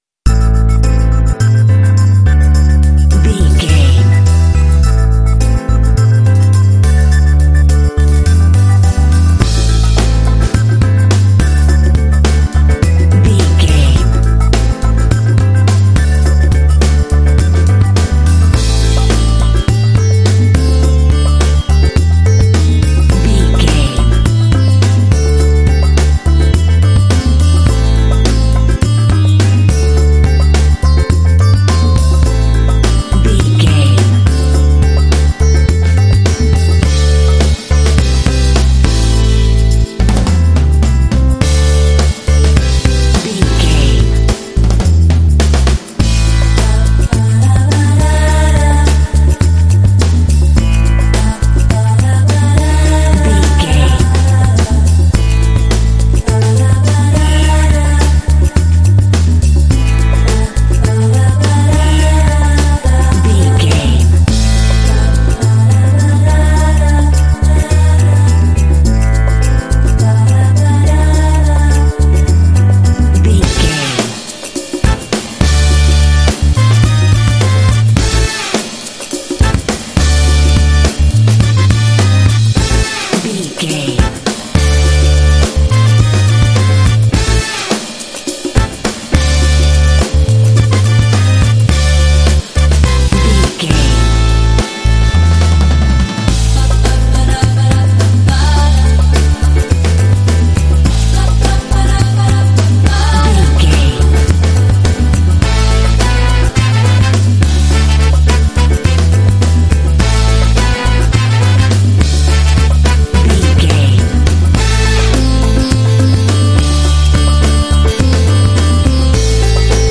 Aeolian/Minor
funky
groovy
sensual
piano
drums
percussion
bass guitar
synthesiser
electric guitar
vocals
brass
r& b
Funk
soul